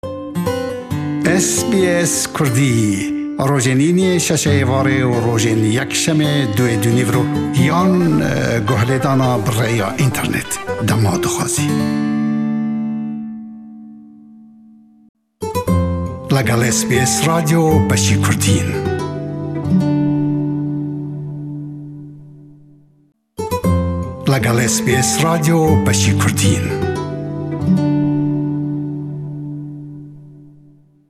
diwa hewallekan rûmall dekat le Hewlêr e we.